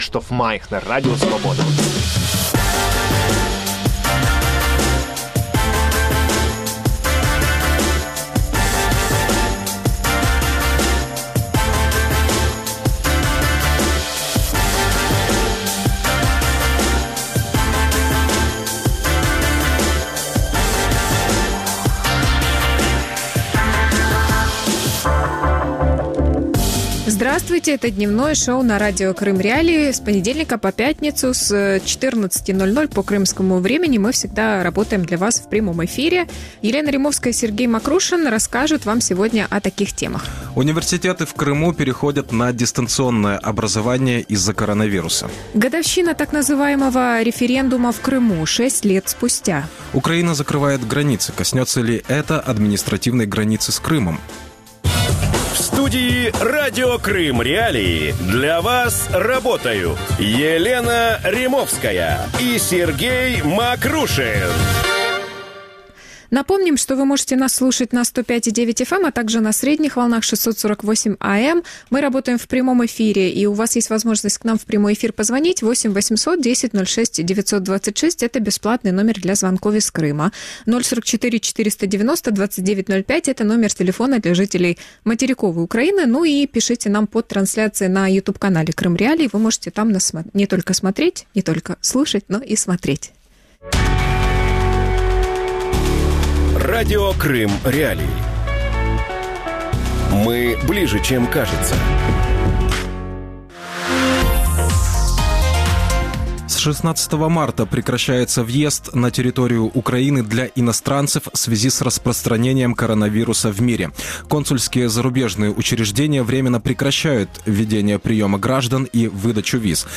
Крым. 6 лет после «референдума» | Дневное ток-шоу